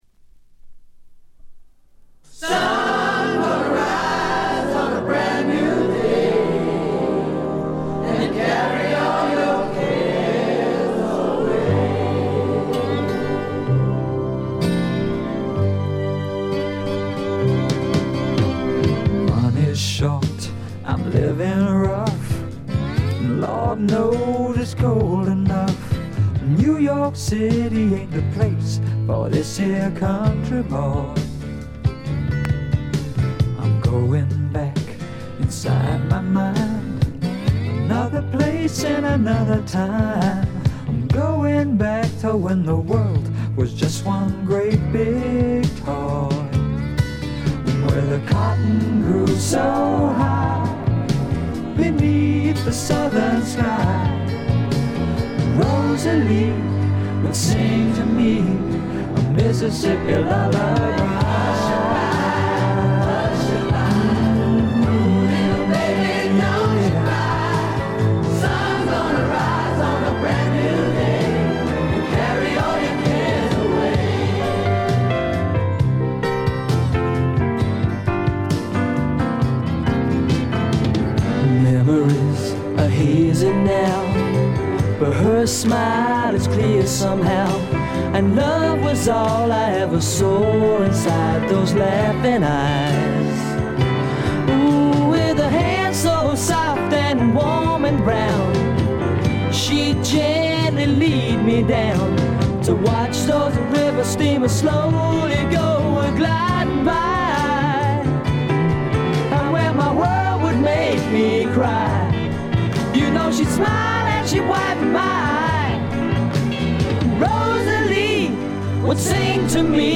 英国のポップ・デュオ
いかにも英国らしい繊細で哀愁感漂う世界がたまらないです。
試聴曲は現品からの取り込み音源です。